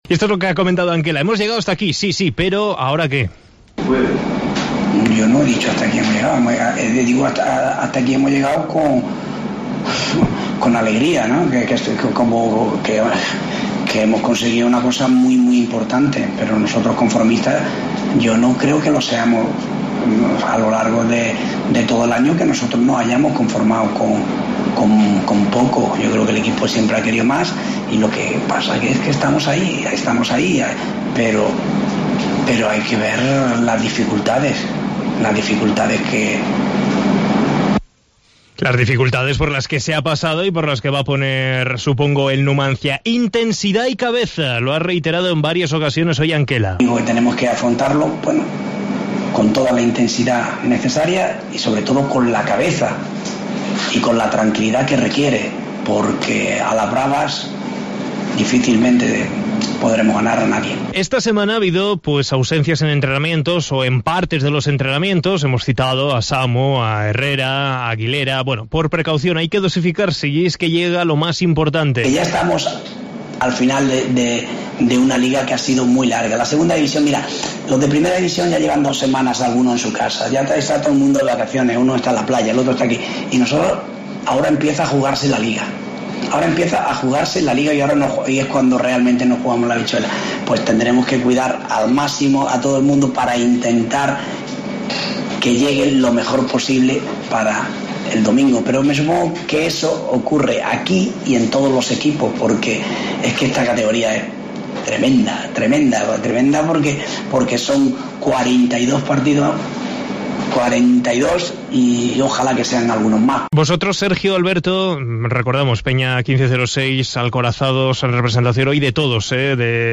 en sala de prensa